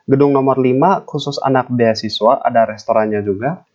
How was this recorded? Until I started to use some cheapy-mic, and it sounds like this Something is heavily processing the microphone sound. It’s going through noise reduction and room echo suppression and that’s what gives it that odd, underwater sound. And both doesn’t sound different, it’s still this bubbly sound.